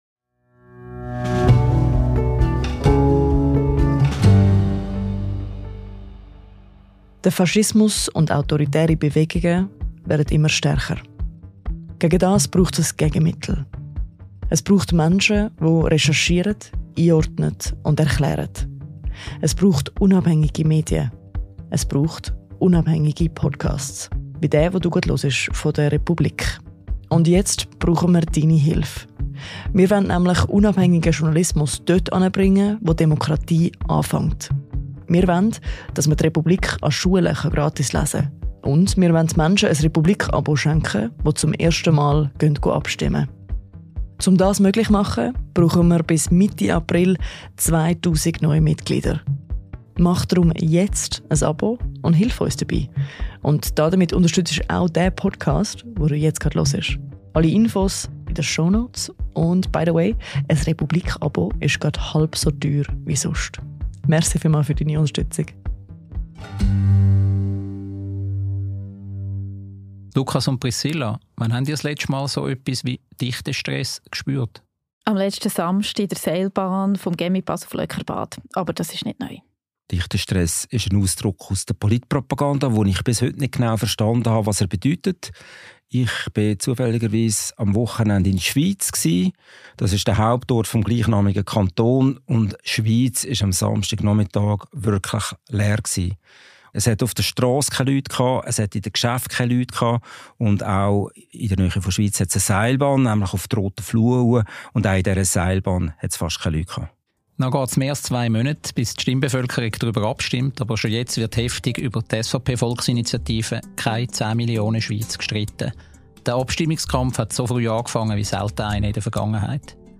Das Team der Republik-«Sondersession» diskutiert die möglichen Folgen der jüngsten SVP-Zuwanderungsinitiative. Versinkt die Schweiz im Chaos – oder löst sie ihre grössten Probleme?